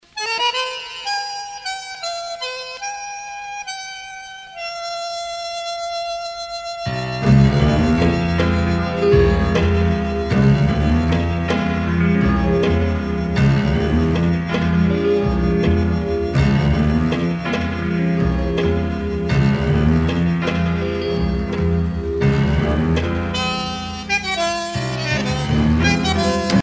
danse : tango (Argentine, Uruguay)
Pièce musicale éditée